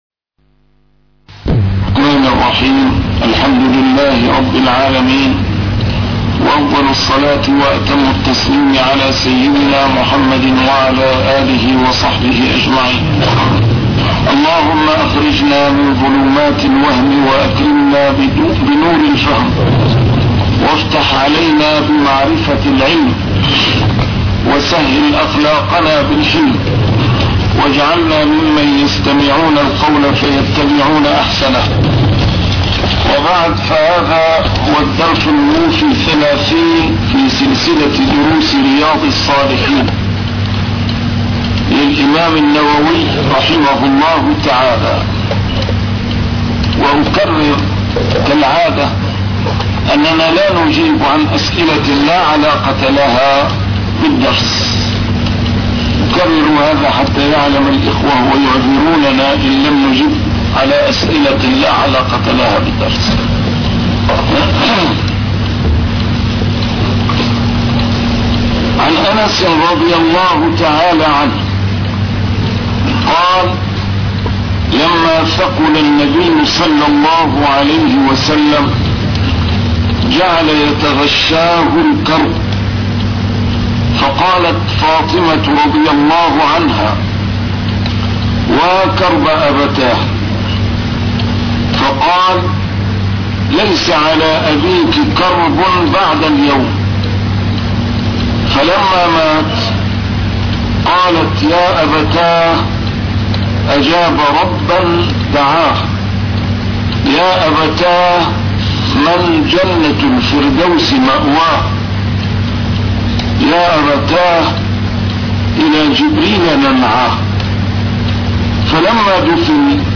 شرح كتاب رياض الصالحين - A MARTYR SCHOLAR: IMAM MUHAMMAD SAEED RAMADAN AL-BOUTI - الدروس العلمية - علوم الحديث الشريف - 30- شرح رياض الصالحين: الصبر